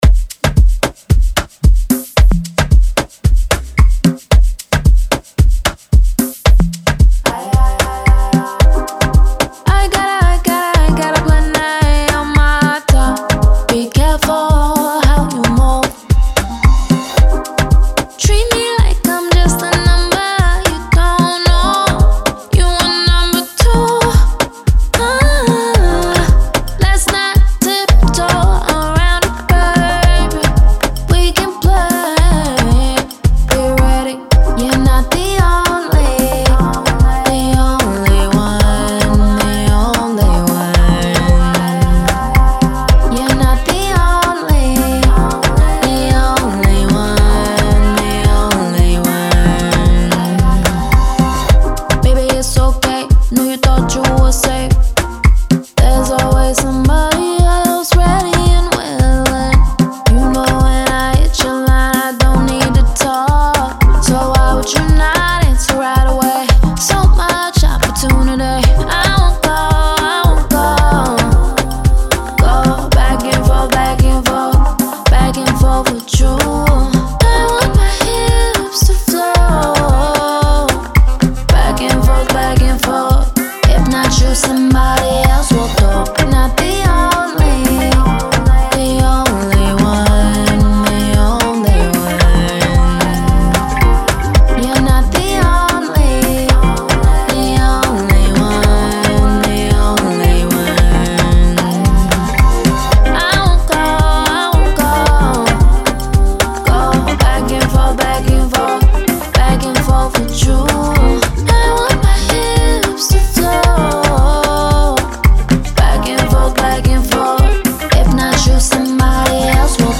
Afrobeat
C Minor